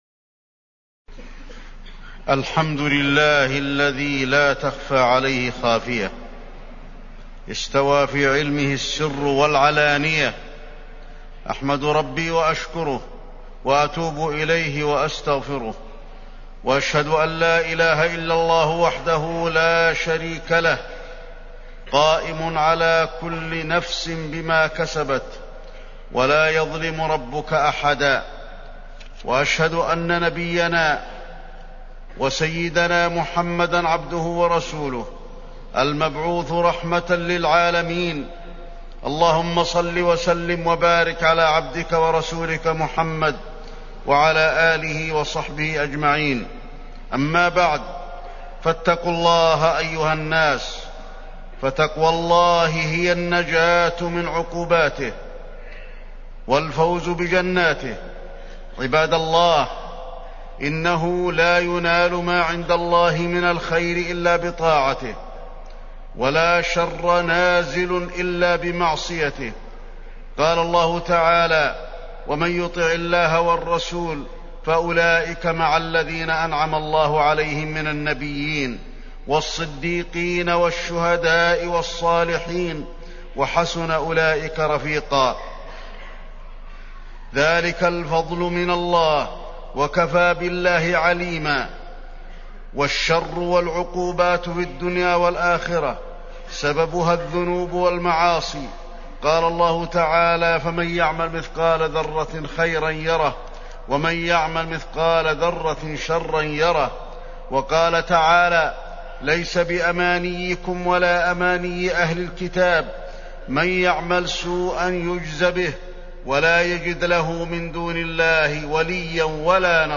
تاريخ النشر ١٩ شوال ١٤٢٧ هـ المكان: المسجد النبوي الشيخ: فضيلة الشيخ د. علي بن عبدالرحمن الحذيفي فضيلة الشيخ د. علي بن عبدالرحمن الحذيفي أثر الذنوب والمعاصي